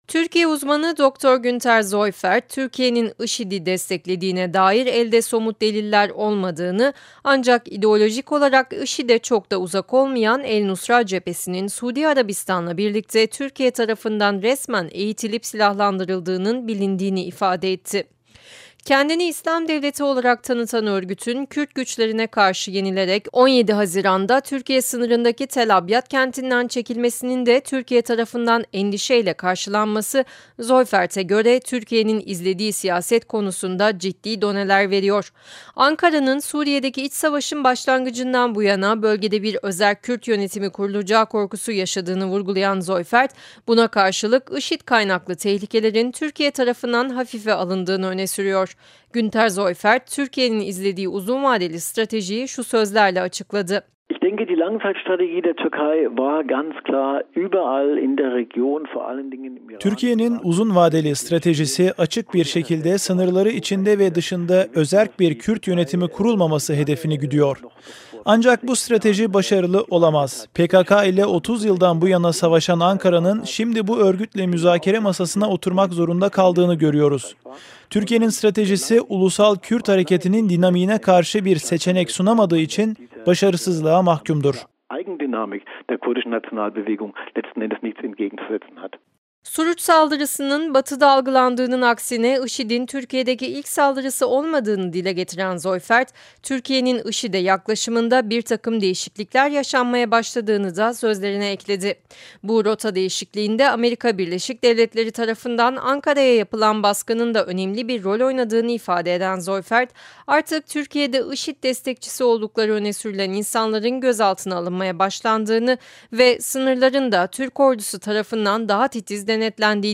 ‘Ankara’nın stratejisi başarısızlığa mahkum’ Radyo Haber | Akademi Portal